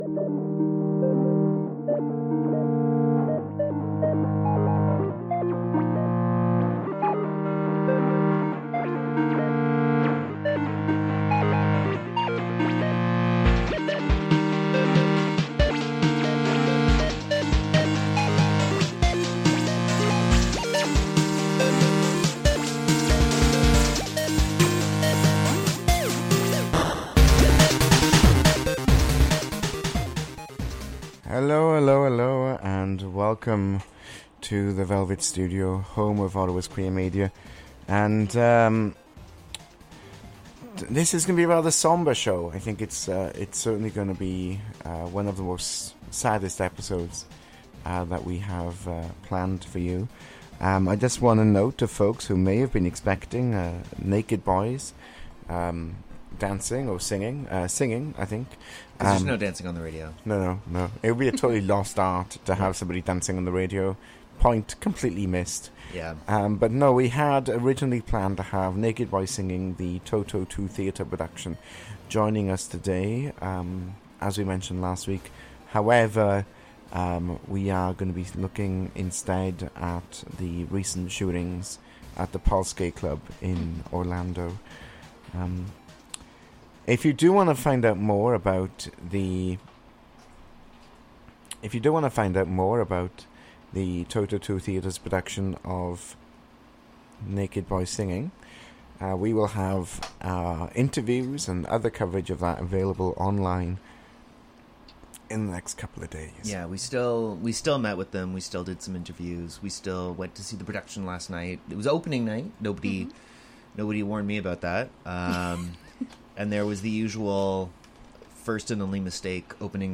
The Velvet Studio Team discusses the nuances of the shooting, and reads the names.